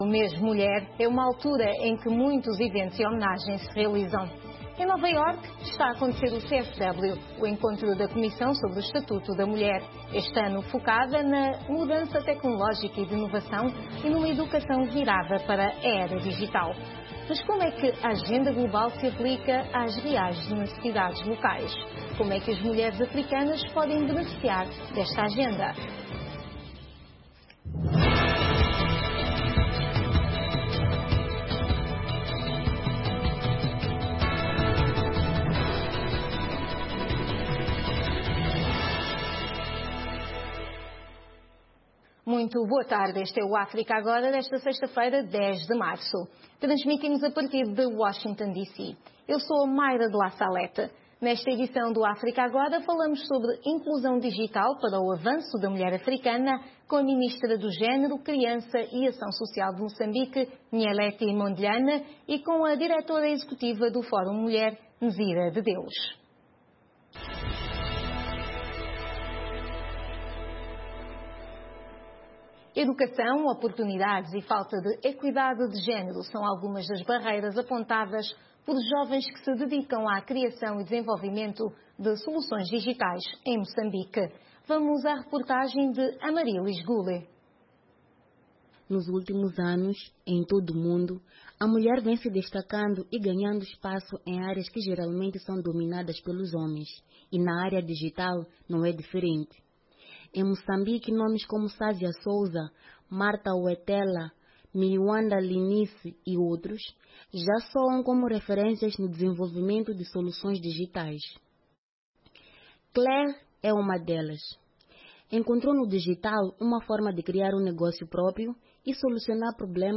Um debate sobre temas actuais da África Lusófona.